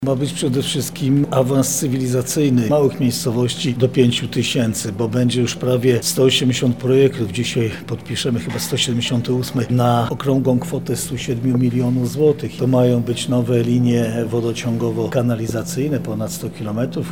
O efektach prac mówi marszałek województwa lubelskiego, Jarosław Stawiarski: